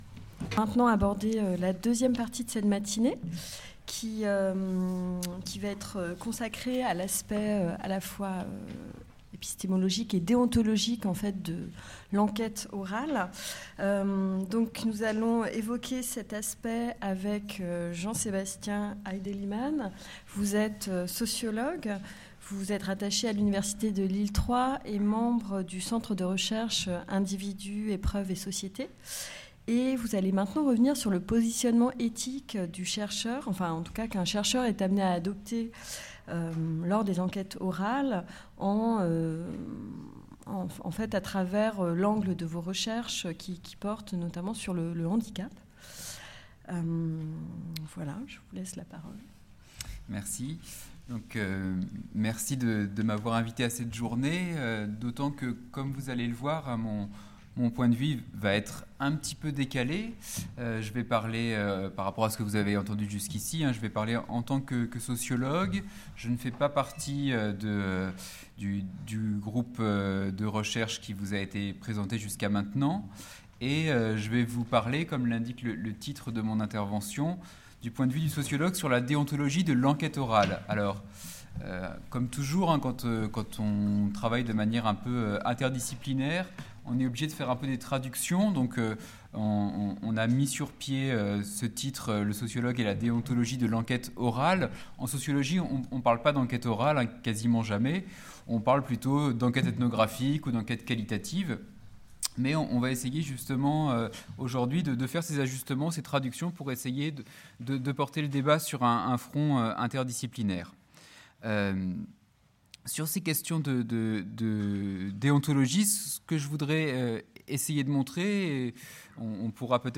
Cette journée d'étude est coordonnée par les missions Action culturelle et bibliothèque numérique de la BULAC et le CERCEC, en partenariat avec RFI et les éditions Autrement, avec le concours de la mission Communication externe de la BULAC.